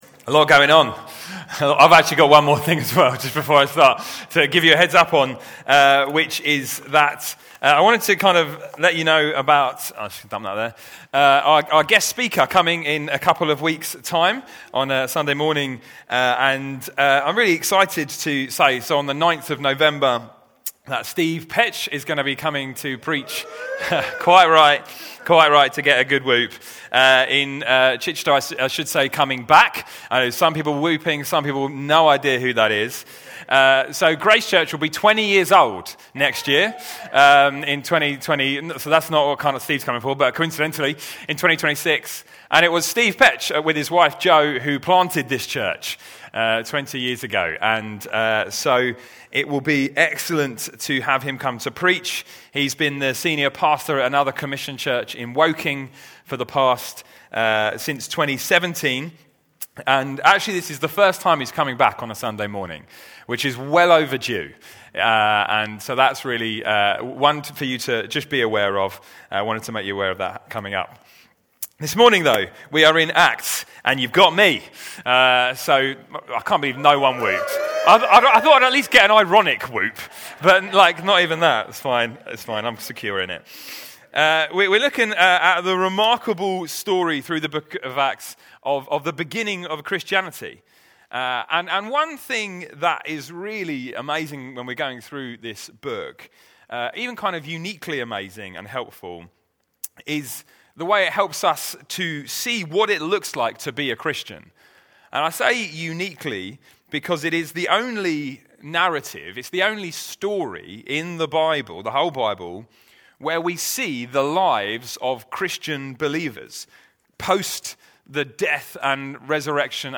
Grace Church Sunday Teaching